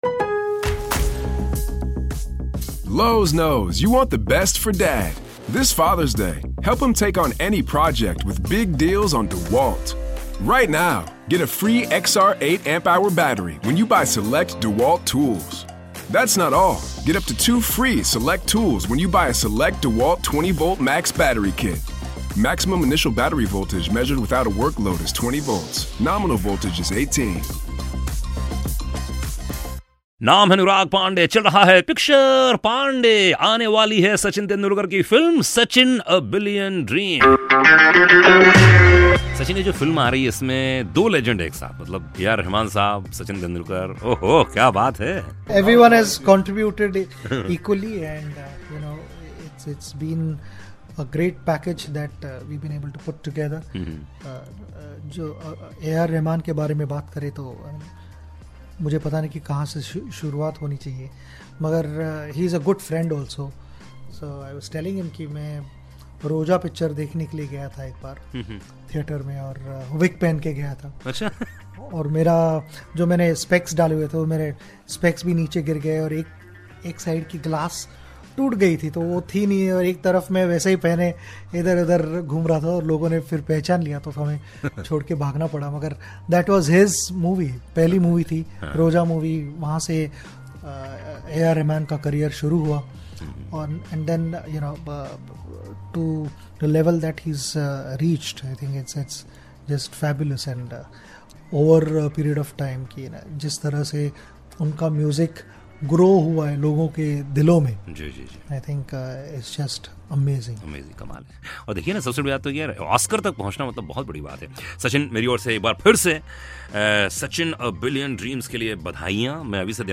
Interview With Sachin For "Sachin: A Billion Dreams" - Link 4